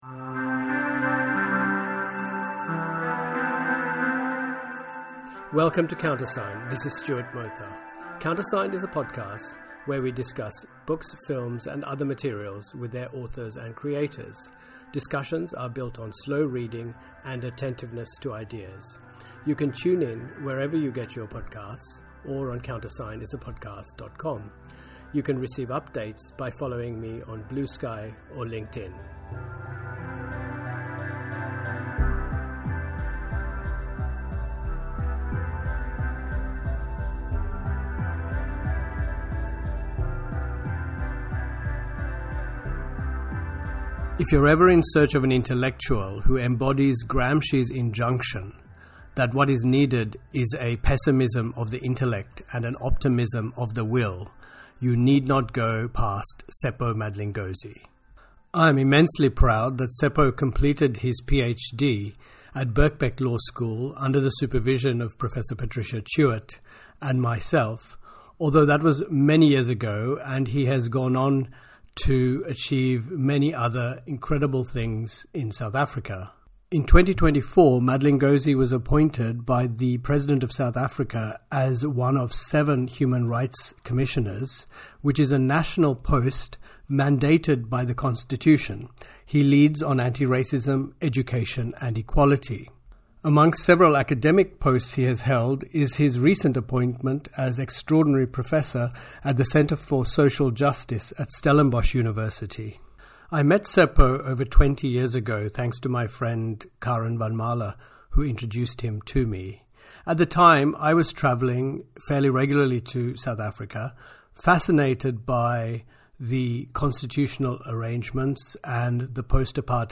Subjects covered include the post-human, history, empire, migration, belonging, ecology, and technology. These conversations draw on philosophical and political theories so they can inform our thinking about contemporary challenges and problems.